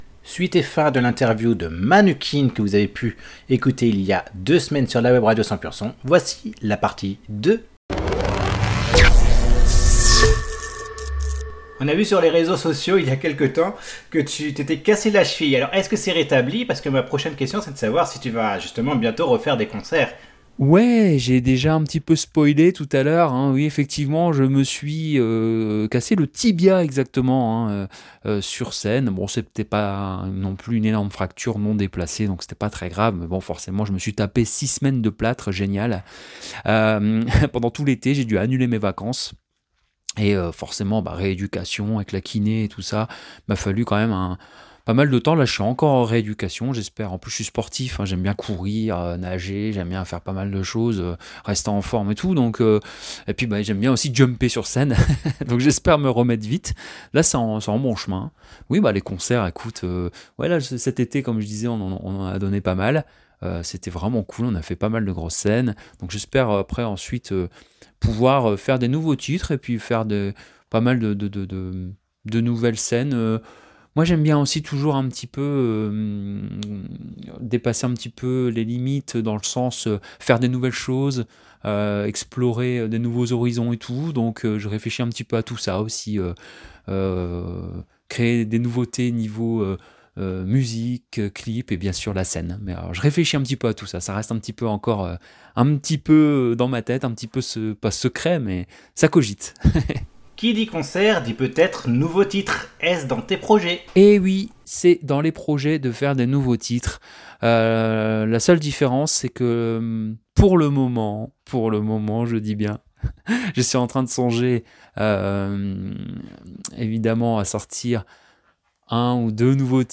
Voici la 2ème Partie de l'Interview